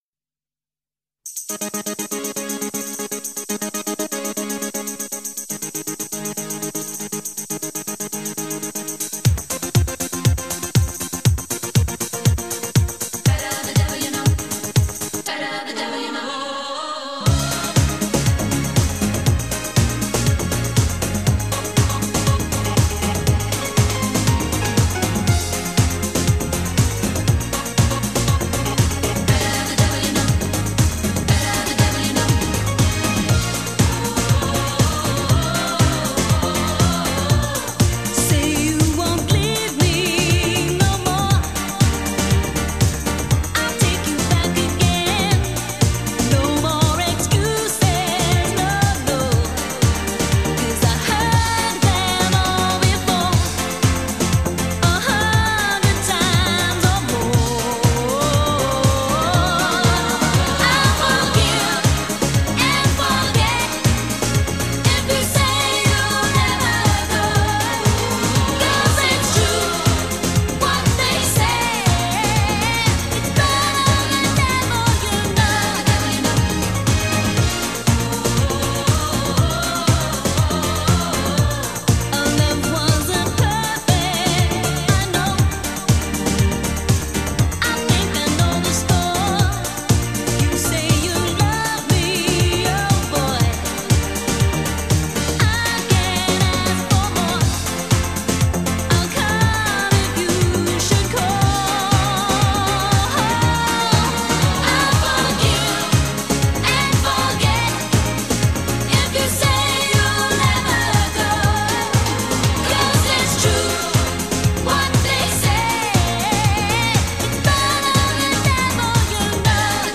依旧是动感的舞曲。